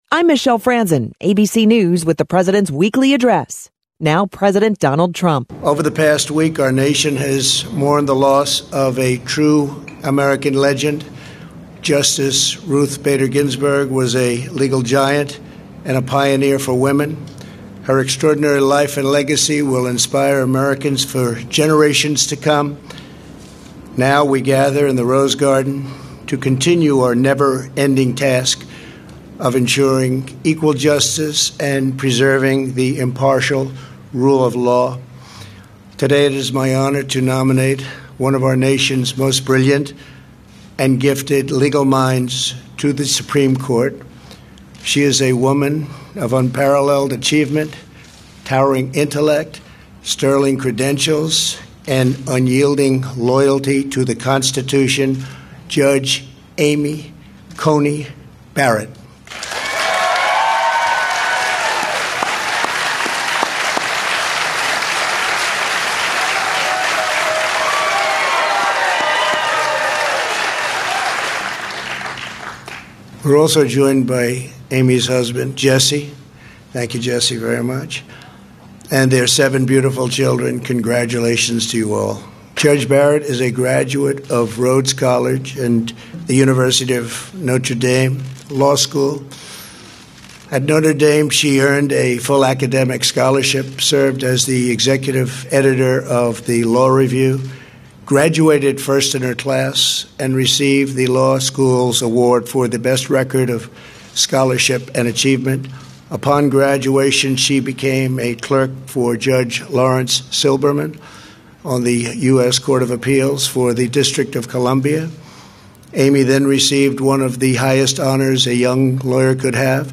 On Saturday, President Trump announced his nominee for Associate Justice of the Supreme Court of the United States.
Here are his words: